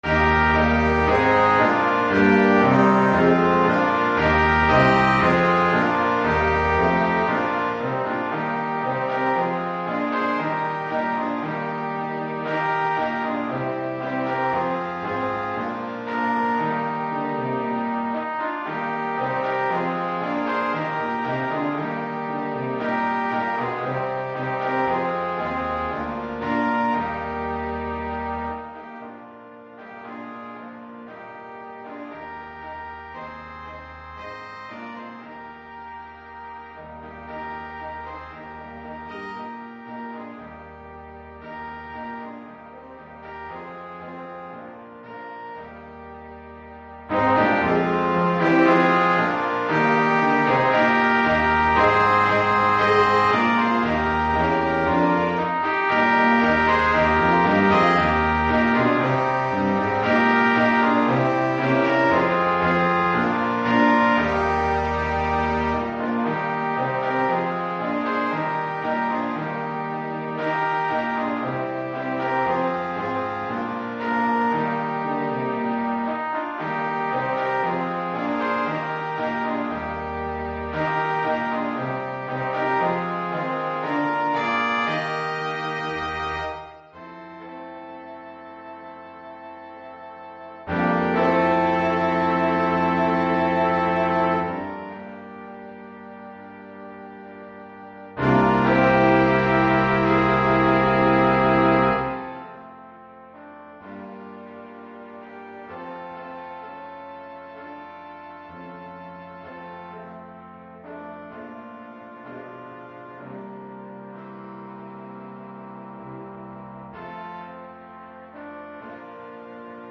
Gattung: 5-Part Ensemble
Besetzung: Ensemble gemischt
Piano, Organ & Percussions optional.